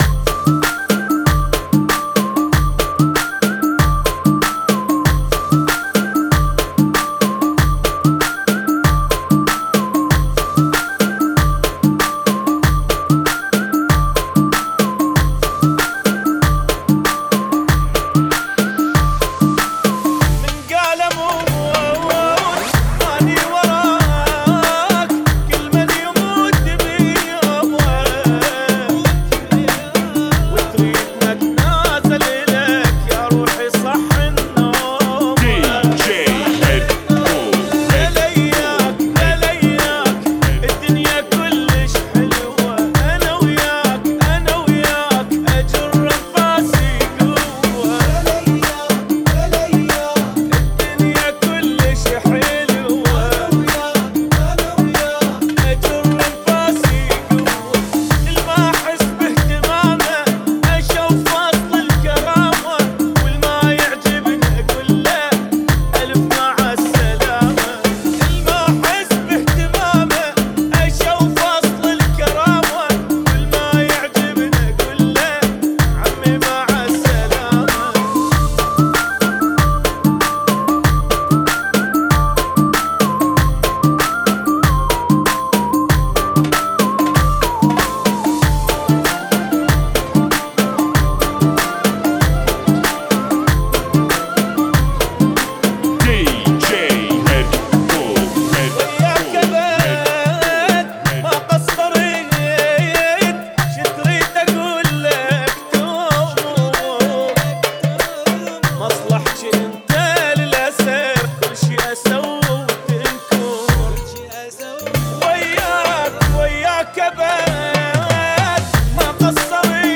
[ 95 bpm ]